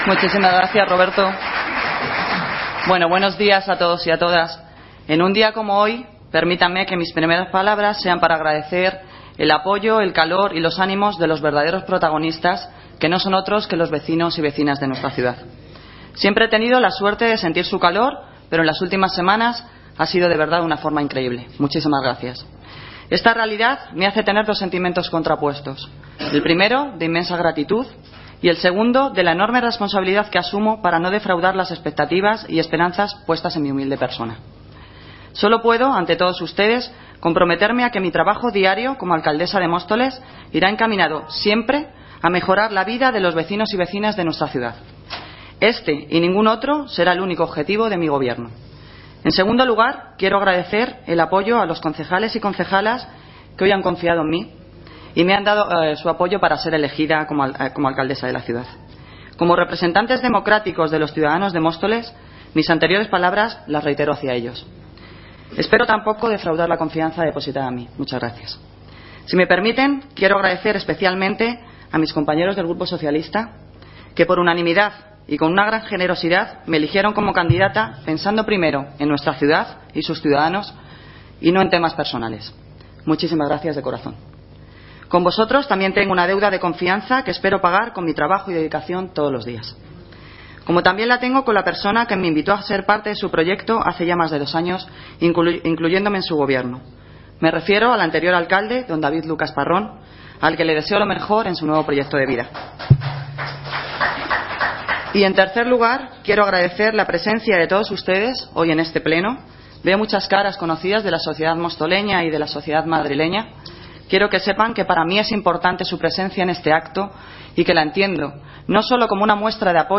Sonido - Noelia Posse (Alcaldesa de Móstoles) Discurso de investidura
Noelia Posse,discurso investidura.mp3